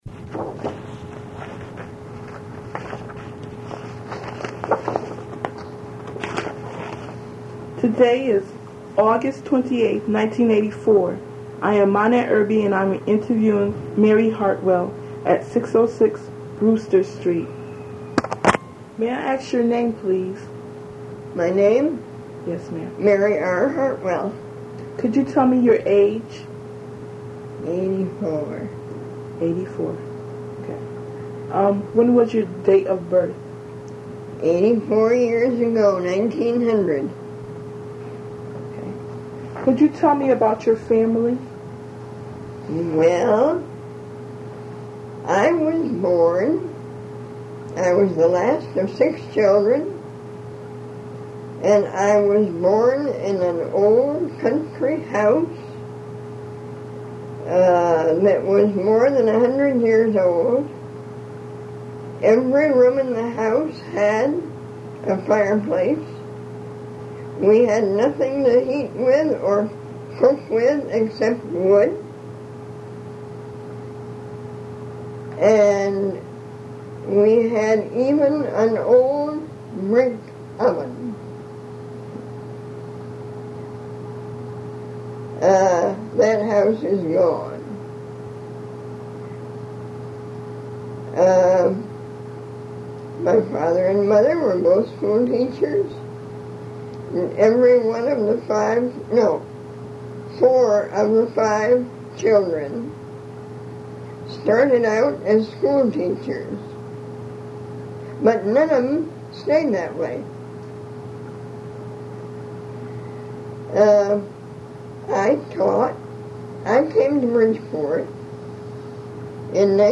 Oral Histories Conducted by Bridgeport Youth in 1984